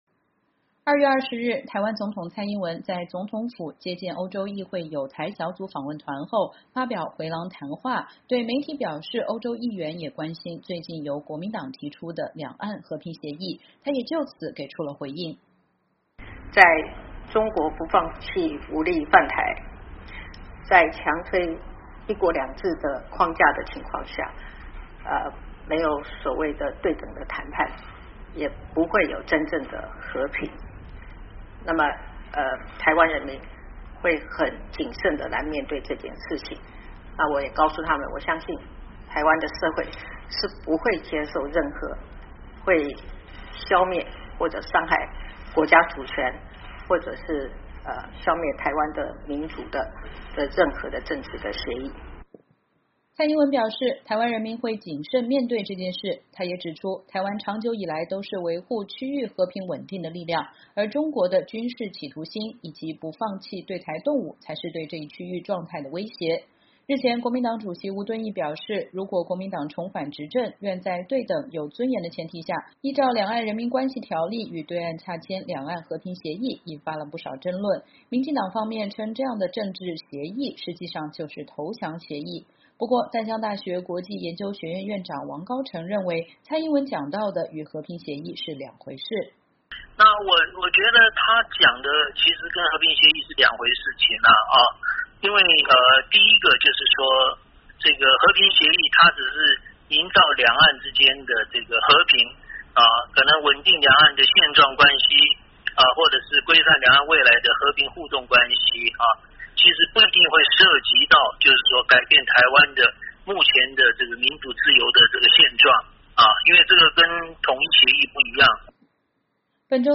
台湾总统蔡英文2月20日针对“两岸和平协议”在总统府发表“回廊谈话”(照片来源：中华民国总统府)